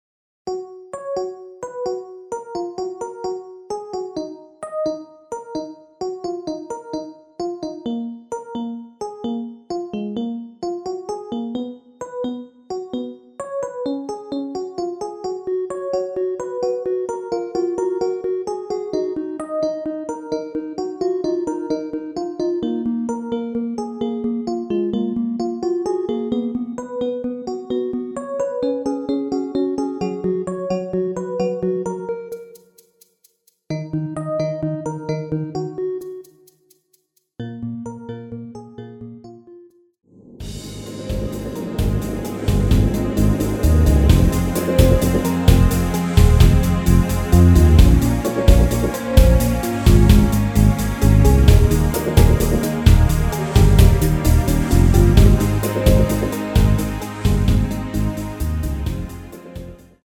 F#
◈ 곡명 옆 (-1)은 반음 내림, (+1)은 반음 올림 입니다.
앞부분30초, 뒷부분30초씩 편집해서 올려 드리고 있습니다.